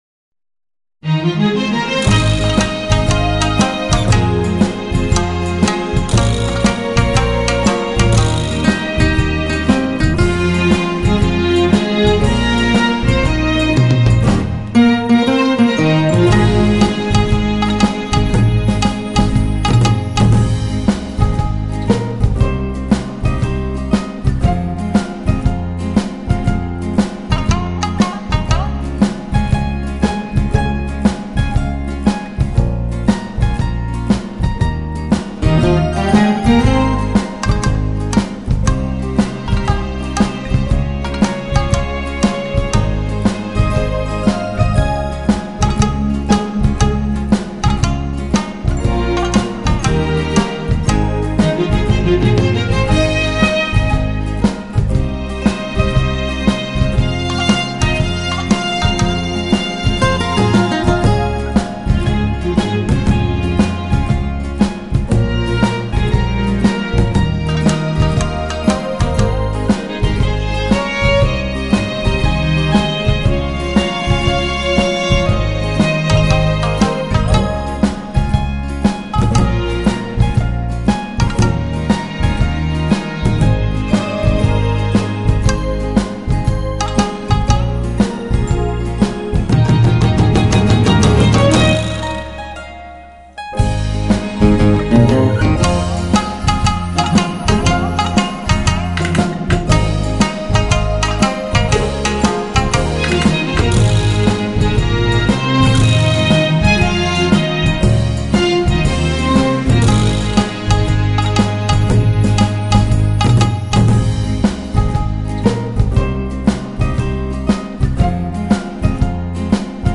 曲調は音頭の様な乗りのいい調子で、年配の方が歌い易くなってます。